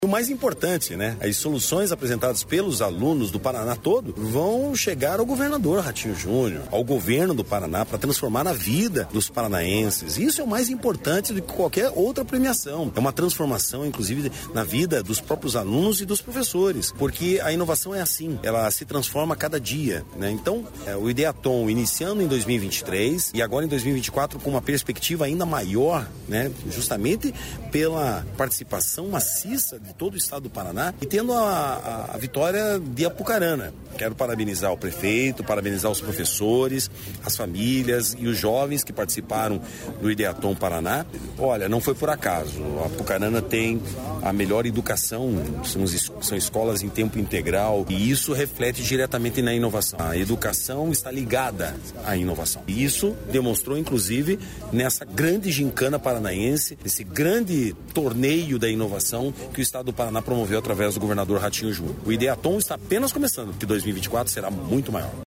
Sonora do secretário da Inovação, Marcelo Rangel, sobre o Ideathon Paraná 2023